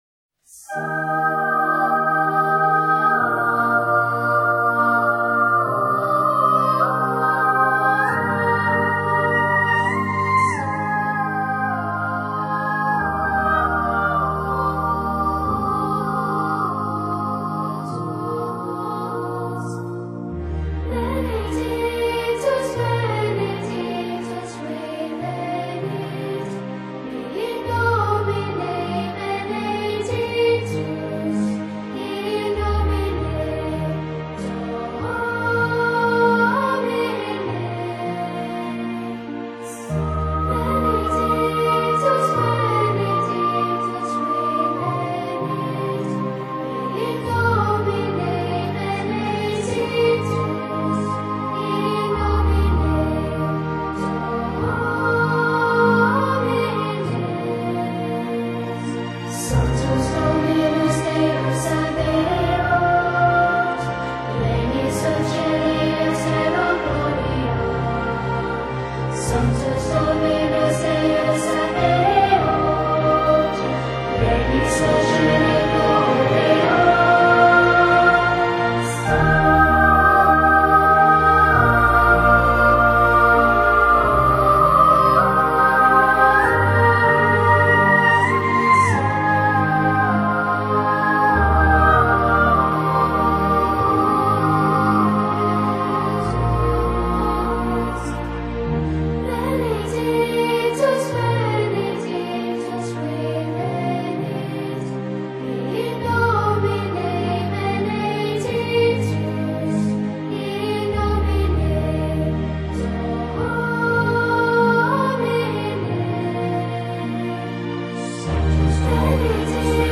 New Age Instrumental
用拉丁文演唱，演唱者全部都是7至14岁的男童